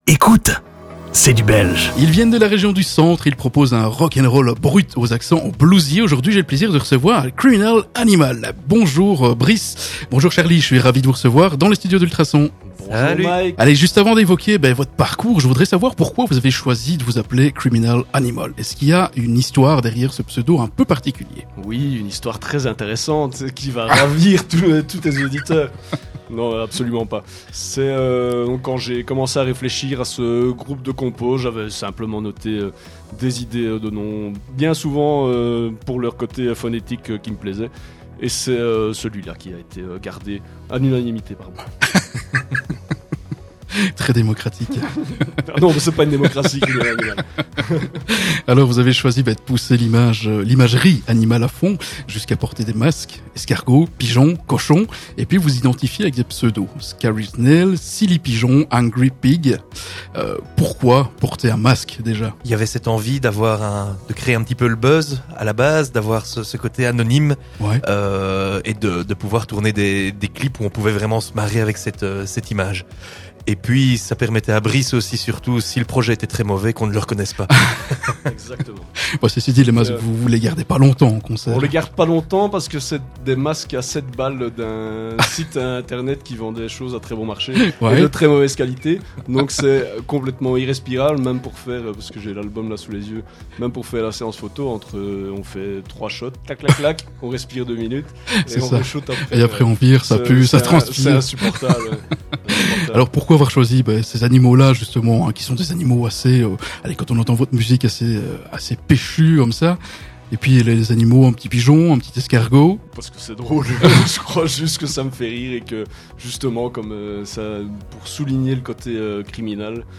Interview / Podcast En ce début d'année 2025, nous avons invité "les bestiaux" pour une interview bien rock 'n' roll.